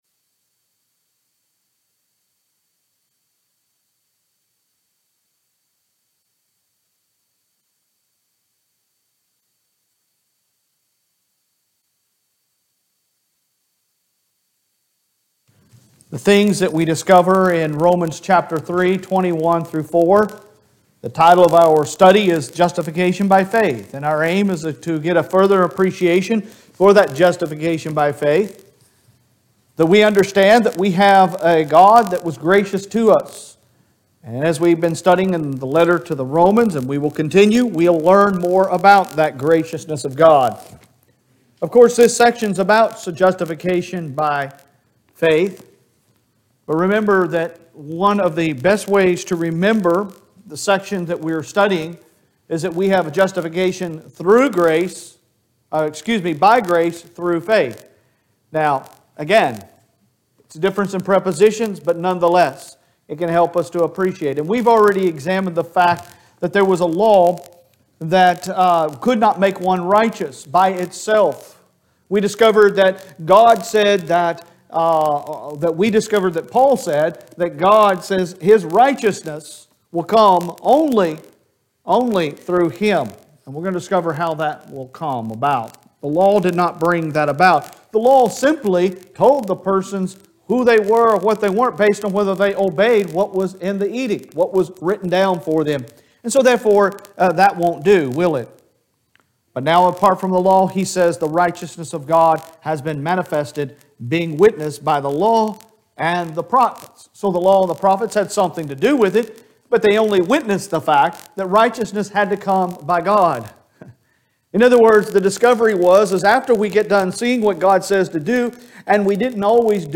Sun PM Sermon 01.09.22